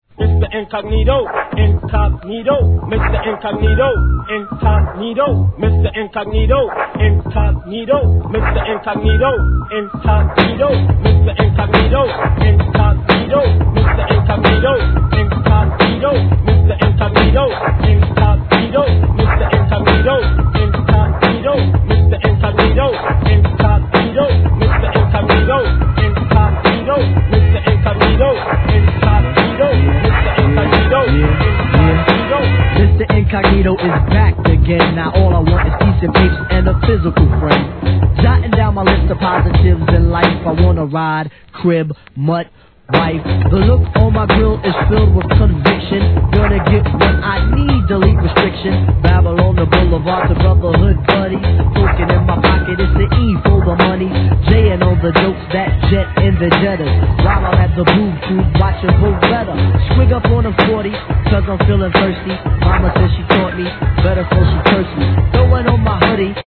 HIP HOP/R&B
素晴らしいJAZZネタにフックのLOOPたまりません!!!